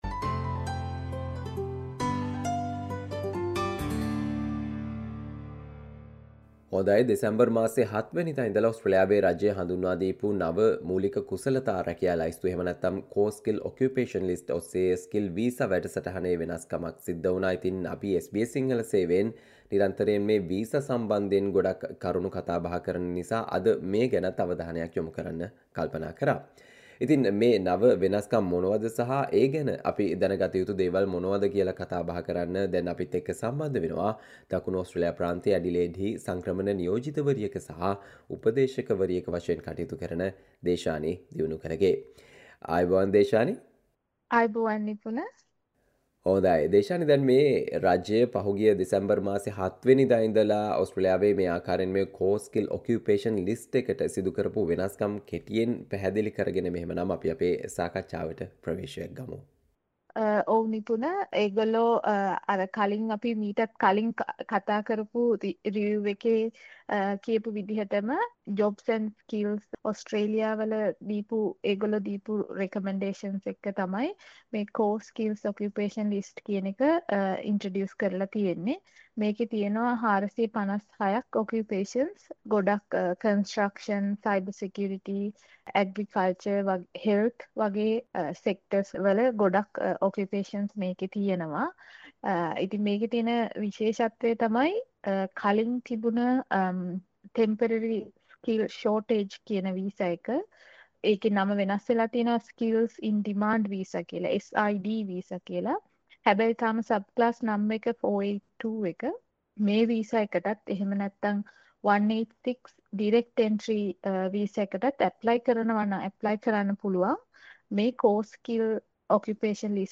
SBS Sinhala discussion on Top visa options for migrating to Australia in 2024